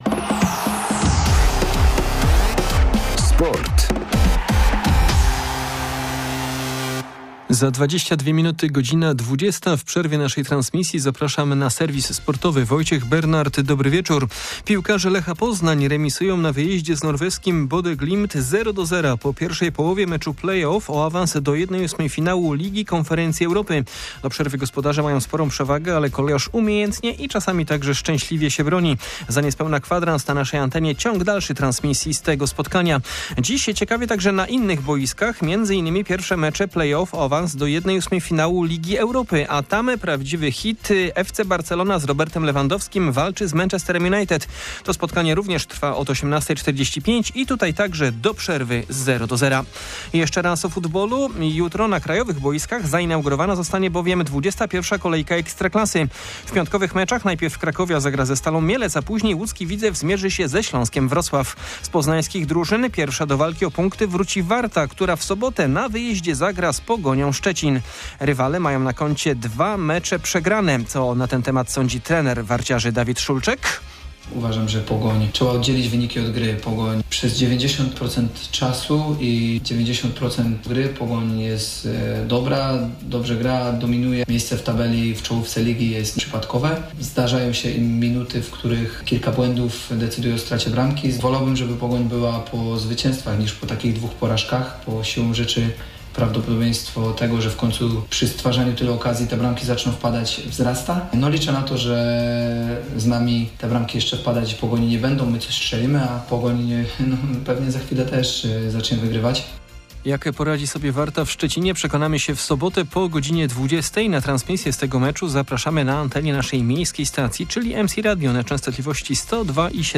16.02.2023 SERWIS SPORTOWY GODZ. 19:38
Nasz serwis tym razem w przerwie meczu piłkarzy Lecha Poznań z norweskim Bodo/Glimt w Lidze Konferencji Europy. Znajdziemy jednak w nim także trochę miejsca dla Warty Poznań przygotowującej się do ligowego spotkania z Pogonią Szczecin.